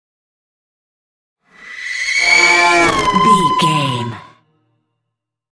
Horror whoosh large
Sound Effects
Atonal
ominous
eerie
whoosh